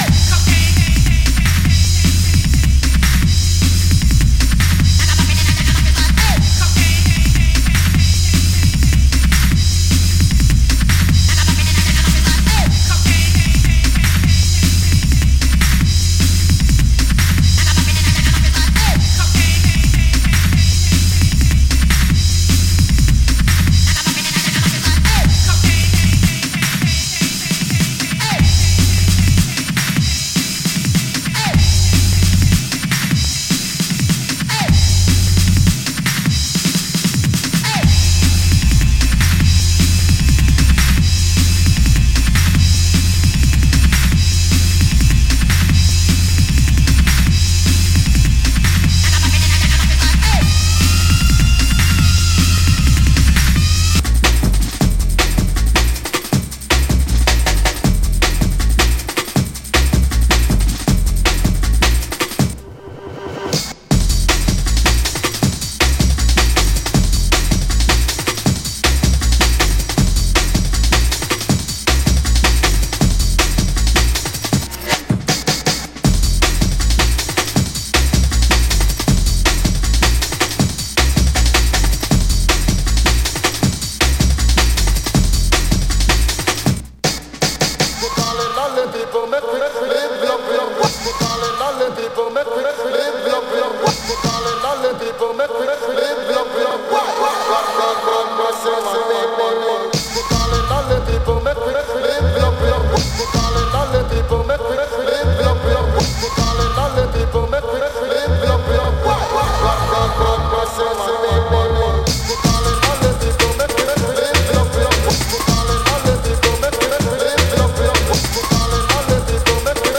Drum N Bass , Jungle , Hardcore , Breakbeat